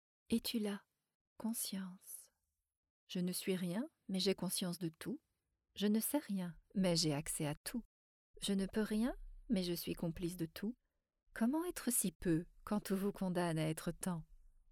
AudiosPoésie